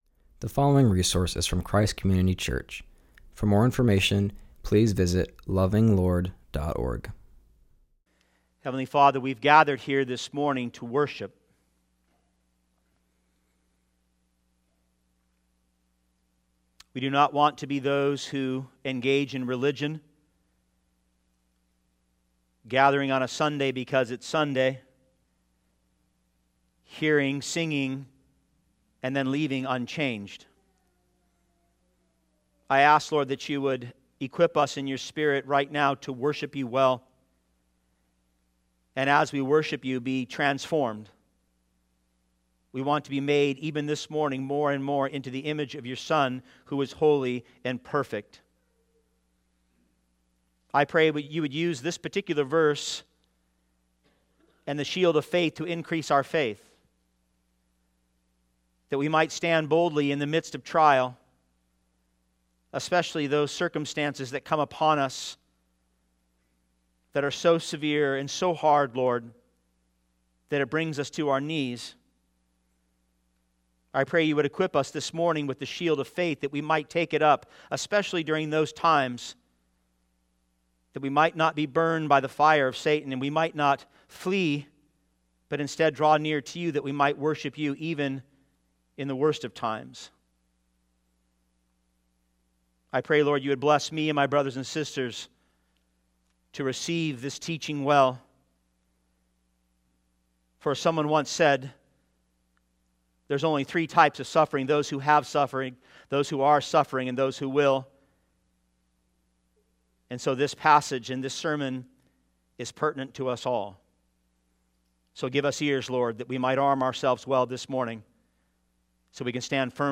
preaches from Ephesians 6:16